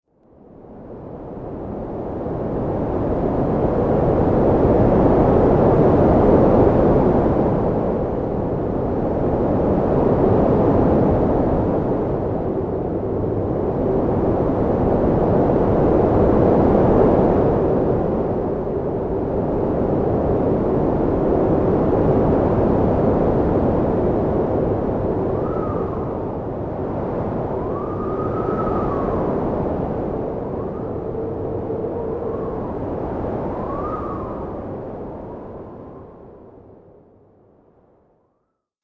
دانلود آهنگ باد 4 از افکت صوتی طبیعت و محیط
دانلود صدای باد 4 از ساعد نیوز با لینک مستقیم و کیفیت بالا
جلوه های صوتی